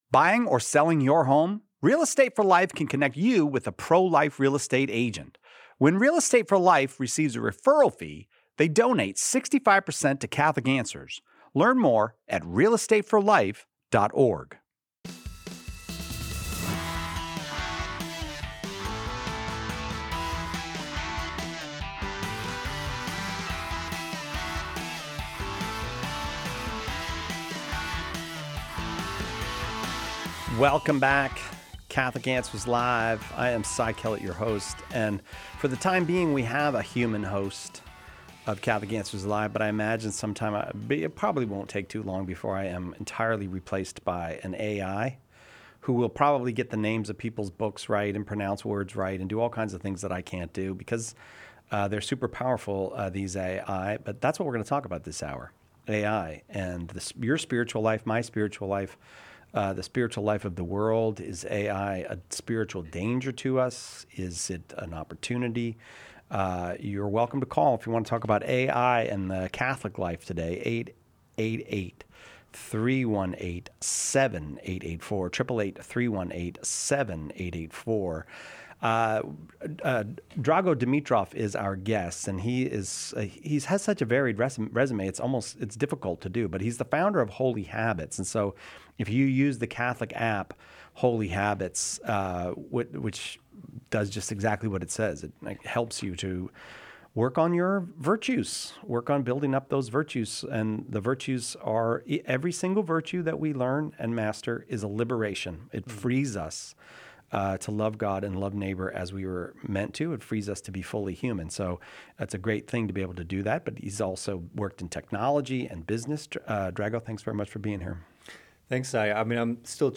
This episode explores the implications of artificial intelligence on faith and communication. Additionally, we delve into how AI can assist in defending the Catholic Church and the challenges it presents in understanding complex theological arguments. Tune in for a thought-provoking discussion on technology and spirituality.